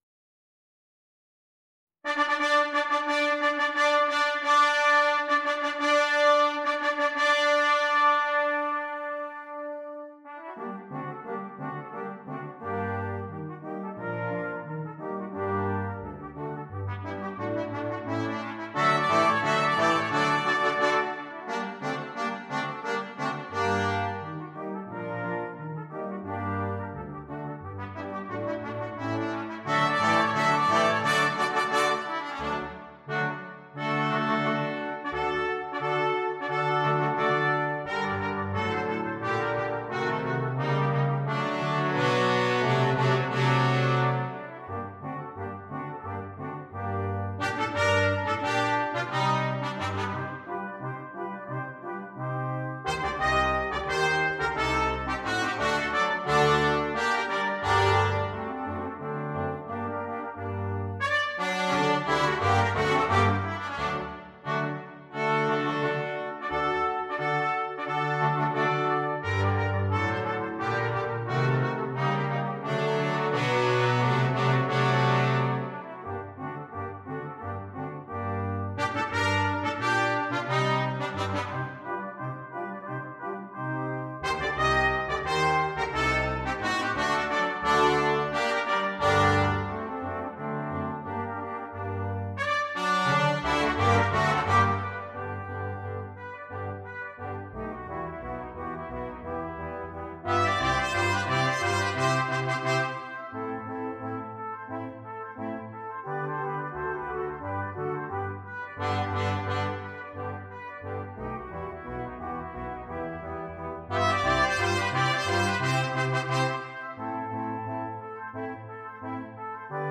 Double Brass Quintet